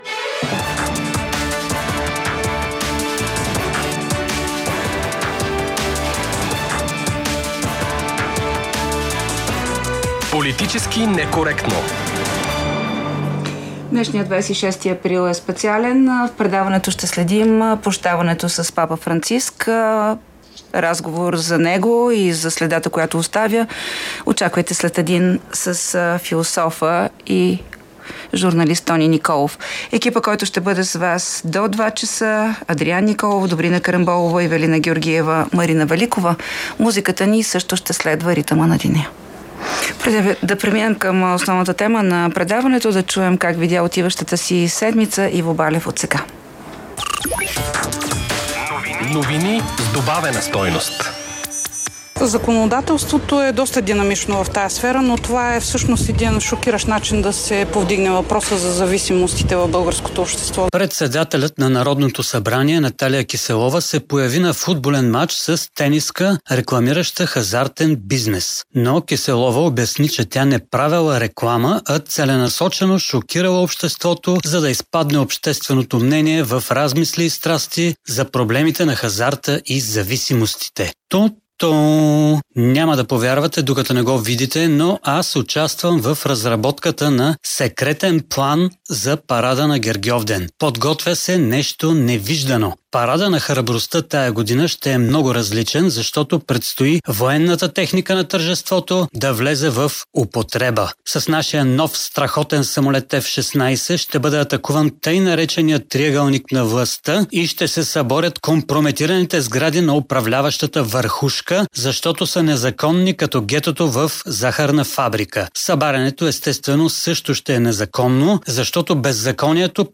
▪ В епизода на „Политически НЕкоректно“ от 26 април 2025 г. коментираме темата трябва ли да се учи религия в училището. Ще чуете коментарите на слушателите относно разпалилата толкова страсти идея на образователното министерство – от първи клас в училище задължително да се изучава предметът „Религии и добродетели“.